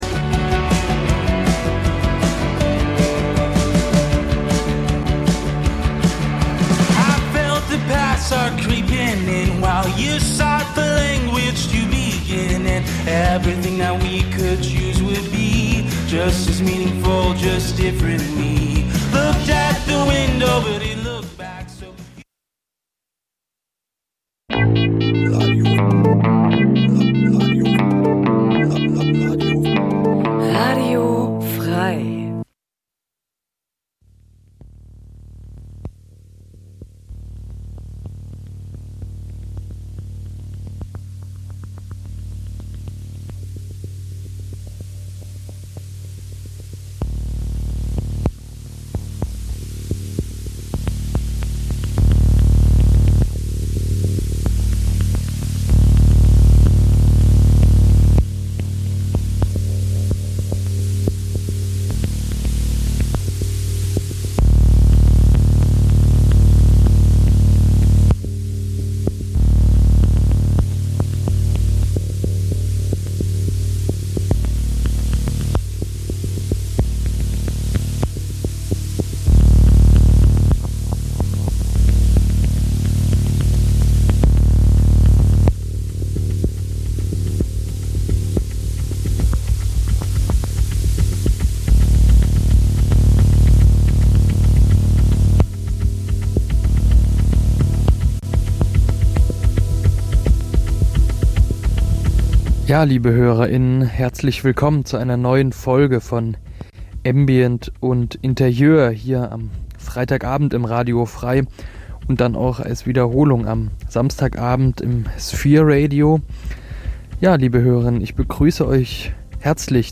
Die meist ruhigen und getragenen elektronischen Kl�nge, der entschleunigte Charakter dieser Musikrichtung, erfordern ein �bewusstes Sich-Einlassen� auf die Musik, einen quasi meditativen Akt, der als musikalische Alternative zur modernen Leistungsgesellschaft gesehen werden kann.